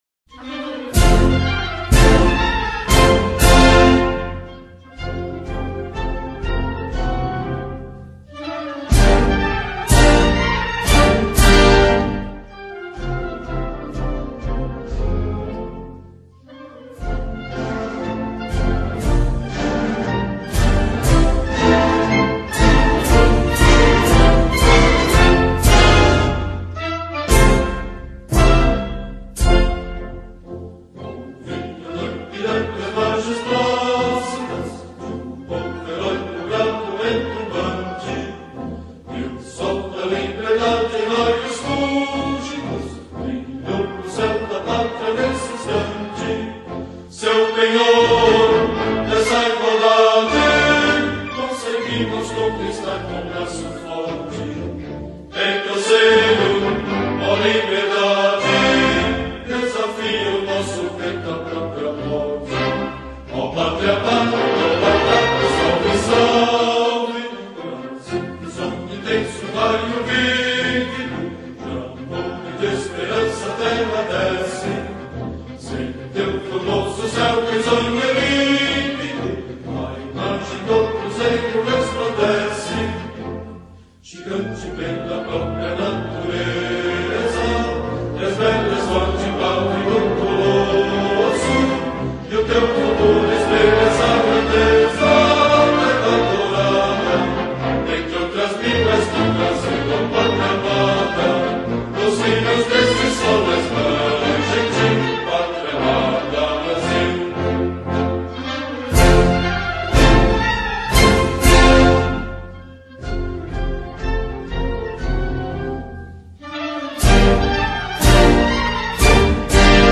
Музыка гимна Бразилии с текстом